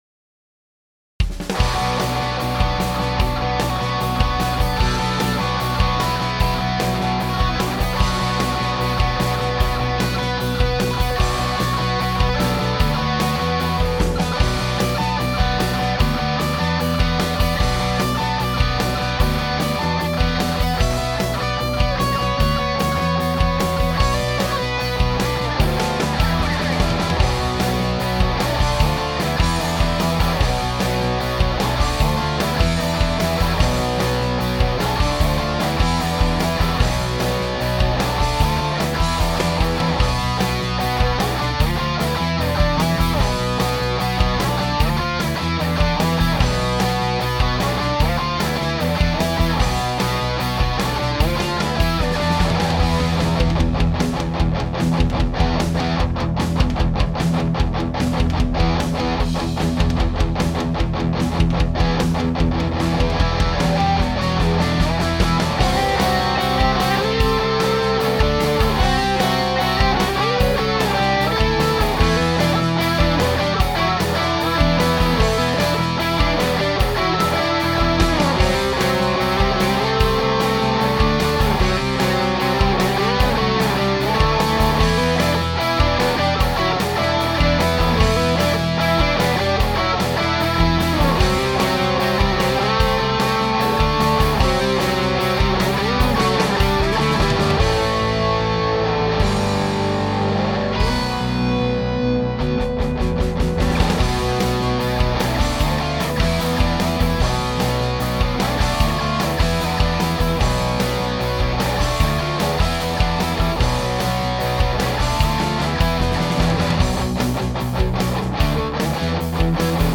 -6db low shelf at 150. +6db high shelf at 5000.